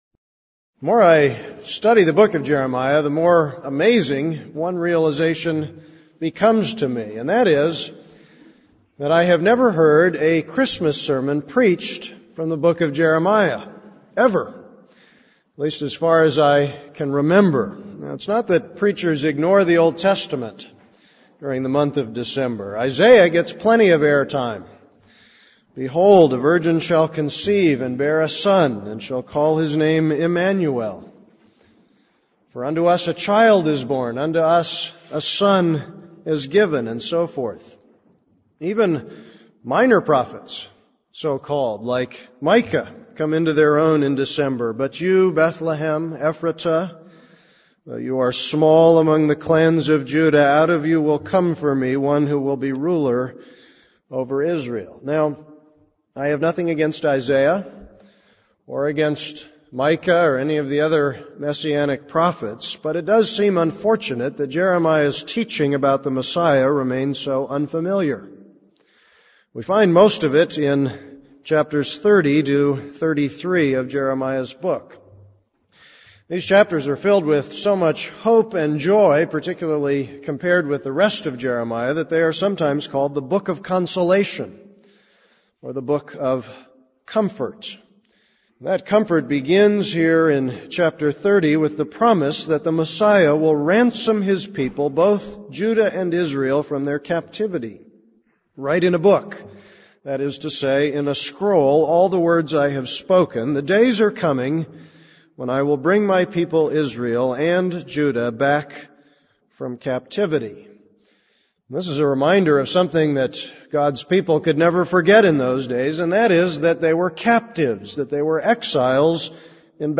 This is a sermon on Jeremiah 30:8-9.